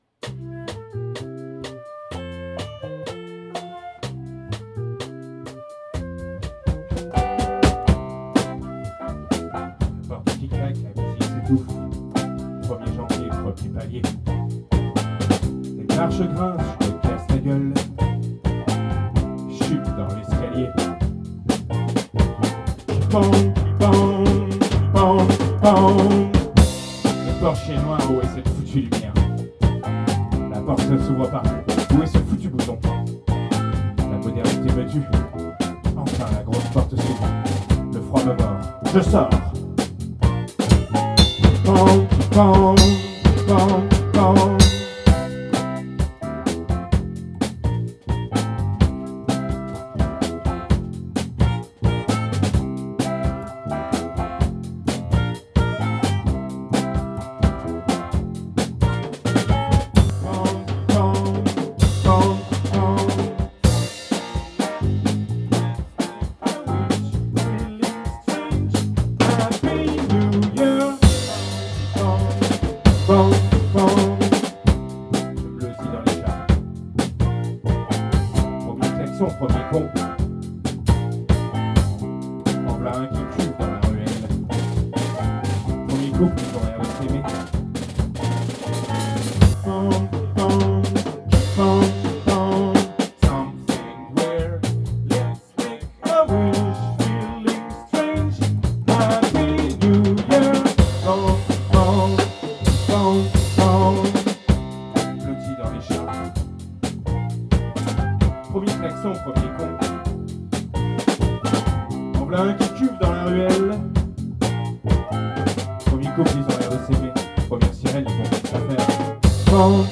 Tempo soutenu.